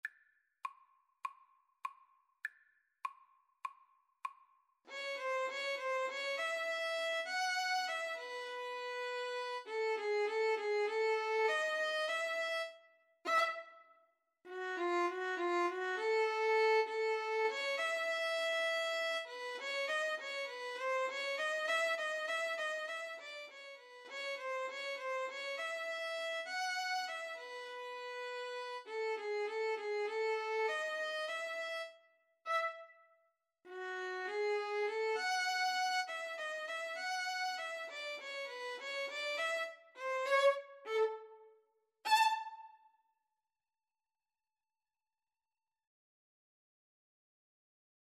A light-hearted Ragtime-style piece.
Violin-Viola Duet  (View more Easy Violin-Viola Duet Music)
Jazz (View more Jazz Violin-Viola Duet Music)